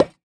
Minecraft Version Minecraft Version latest Latest Release | Latest Snapshot latest / assets / minecraft / sounds / block / decorated_pot / insert1.ogg Compare With Compare With Latest Release | Latest Snapshot